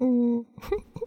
害羞1.wav
害羞1.wav 0:00.00 0:01.07 害羞1.wav WAV · 92 KB · 單聲道 (1ch) 下载文件 本站所有音效均采用 CC0 授权 ，可免费用于商业与个人项目，无需署名。
人声采集素材/人物休闲/害羞1.wav